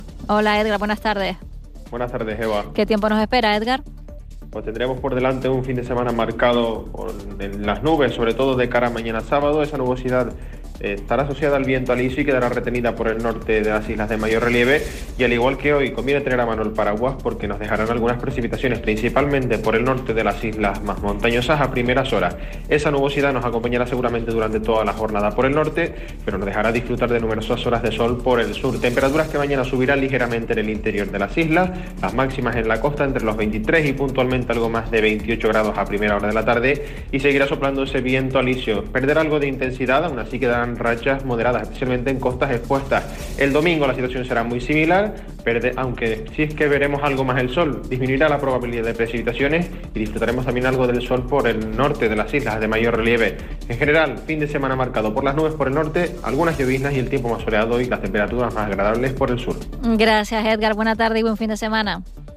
Canario.mp3